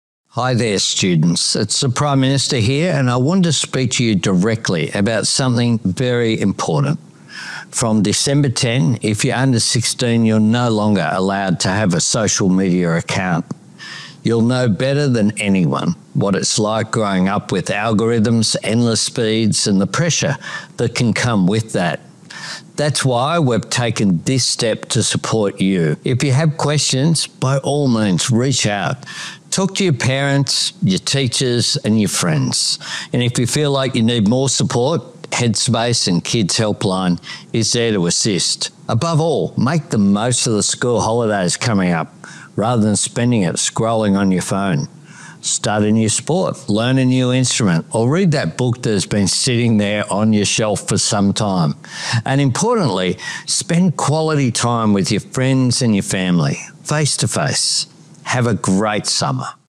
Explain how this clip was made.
Pre-Recorded Message to School Kids on Social Media Ban